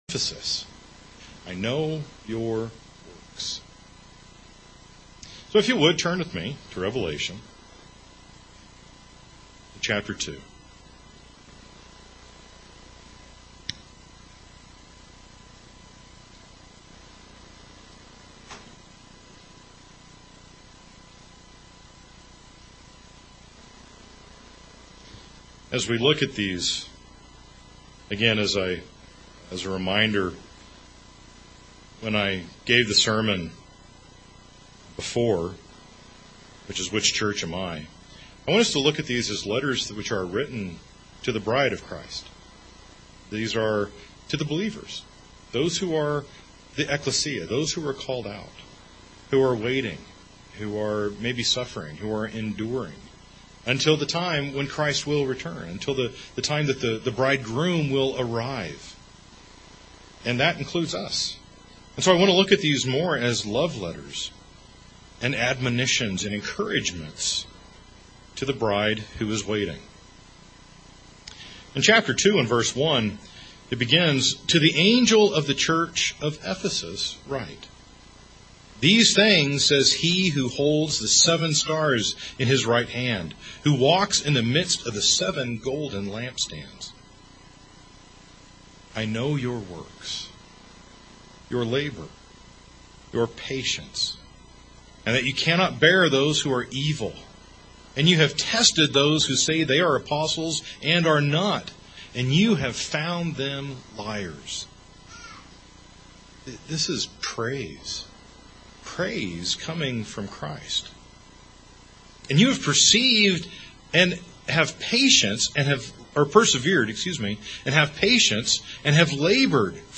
This sermon is the second in the series of "Which Church Am I?". Jesus commends Ephesus for it's works, but warns it to remember it's first love. It is important that we maintain our zeal for the word of God, living in it, and maintaining that manor with our actions and care for others.